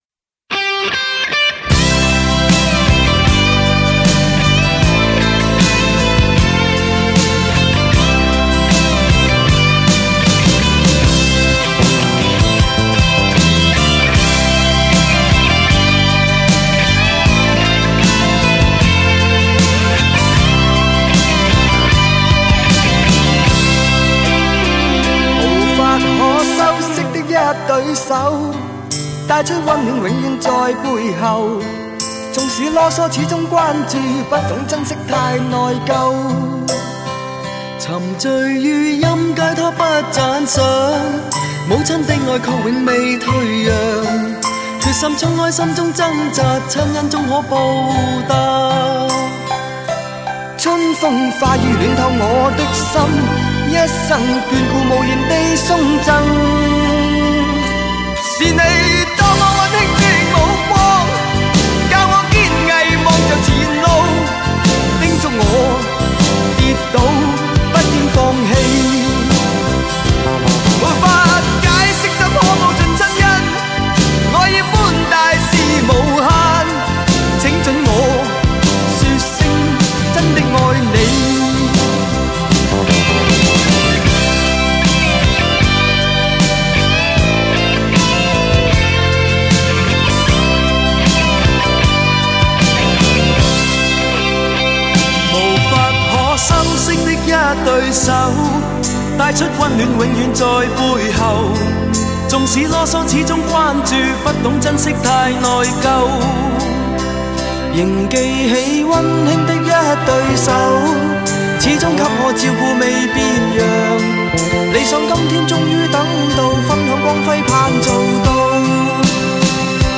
其独特的沙哑嗓音，对尾音的颤抖式处理成为他的标志。
雄伟典范乐曲 必唯天作之合HI-FI典范 极致人声
在音乐方面，和“再见理想”一样，由四位成员各人唱一段。